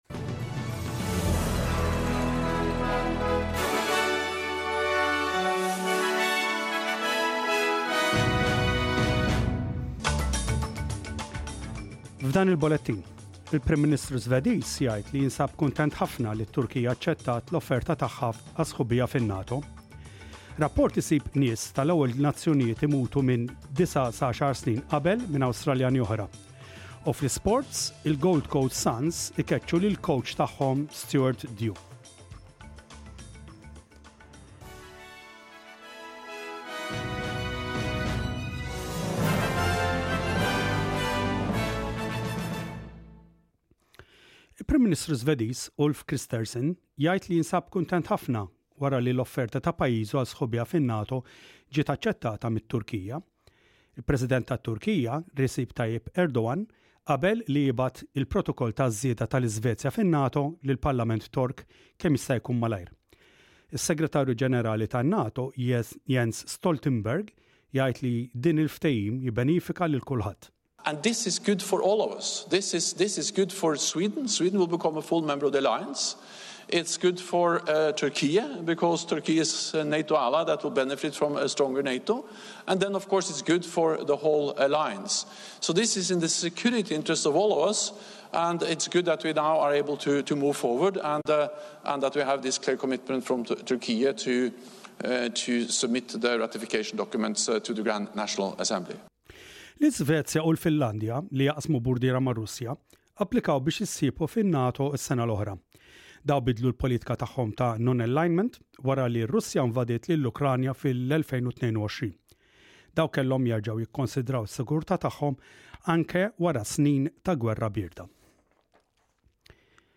SBS Radio | Maltese News: 11/07/23